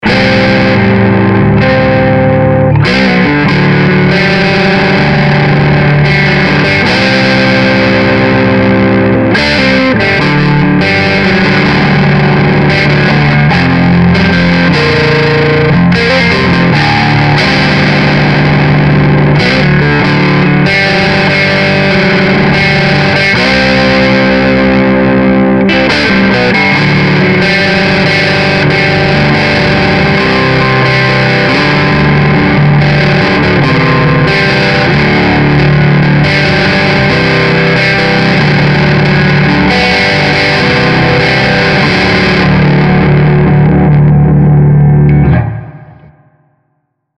Capture of the small yet powerful Krank Rev Jr Pro 50 tube guitar amplifier head.
Crunch
Distortion
RAW AUDIO CLIPS ONLY, NO POST-PROCESSING EFFECTS
Hi-Gain